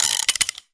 Media:RA_Colt_Evo.wav UI音效 RA 在角色详情页面点击初级、经典和高手形态选项卡触发的音效